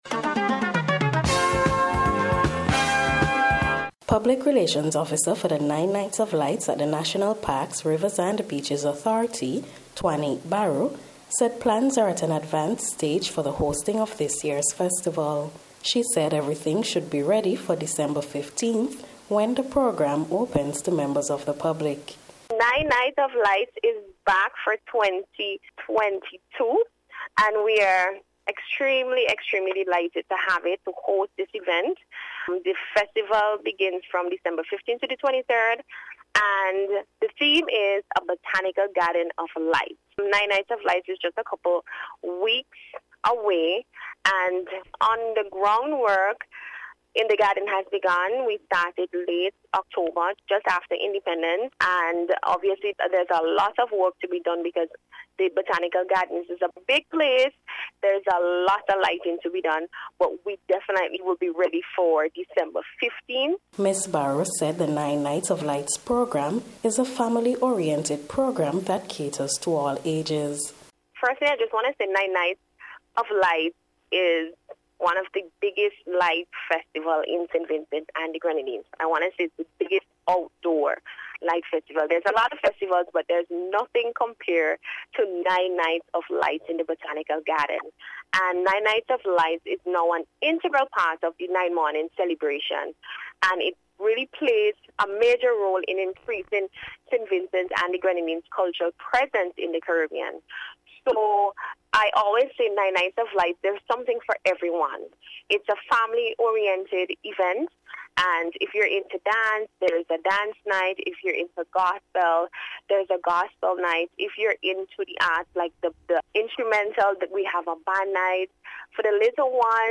NBC’s Special Report – Friday November 11th 2022
NINE-NIGHTS-OF-LIGHTS-2022-REPORT.mp3